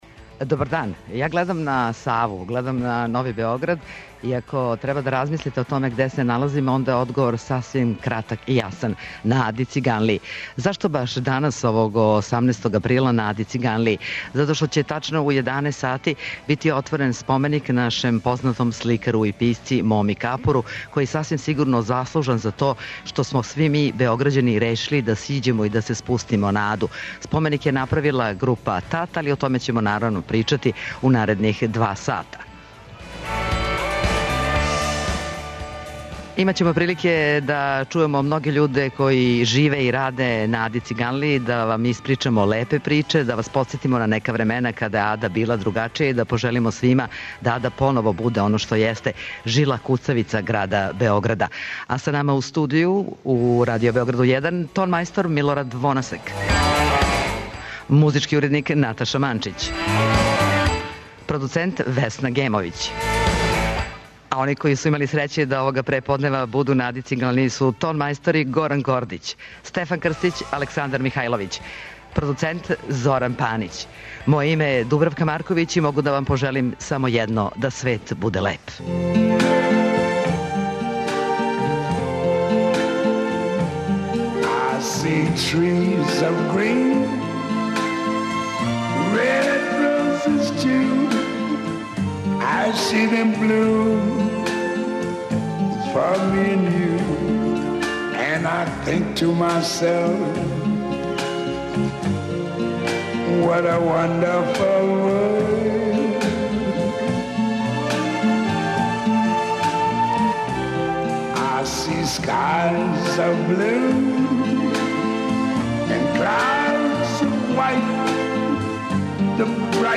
Директно са Аде Циганлије, њене савске стране, подсетићемо вас на време када су Београђани откривали Аду.
У 11 часова пренећемо Вам атмосферу откривања споменика Капору. Чућете и тонске записе из наше архиве, јер је на програмима Радио Београда, Мома био и гост и аутор многих емисија.